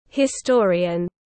Nhà sử học tiếng anh gọi là historian, phiên âm tiếng anh đọc là /hɪˈstɔːriən/.
Historian.mp3